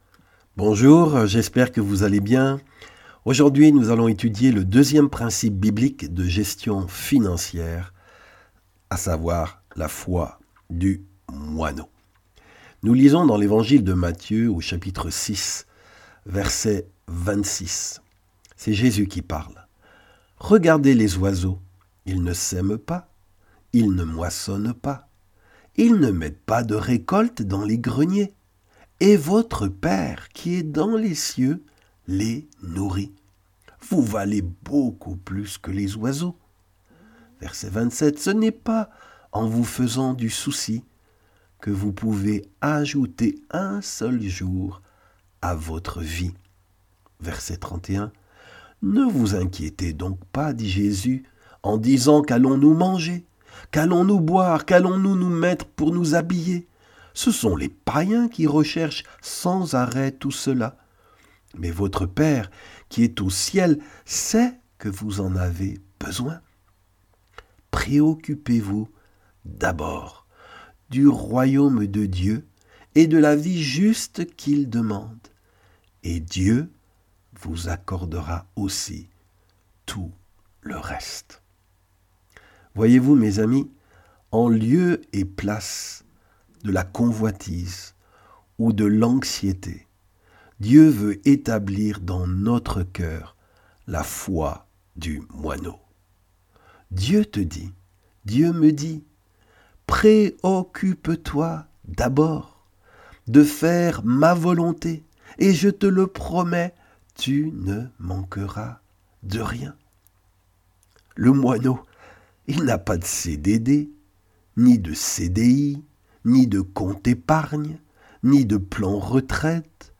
Des messages audio chrétiens